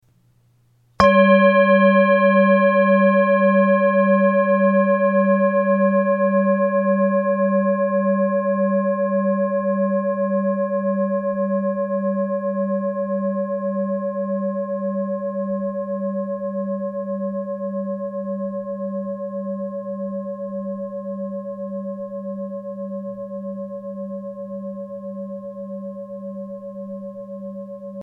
Klangschale Alte tibetische Universalschale 951g KM80-438-KK
ALTE TIBETISCHE KLANGSCHALE - UNIVERSALSCHALE
Grundton: 199,15 Hz
1. Oberton: 565,49 Hz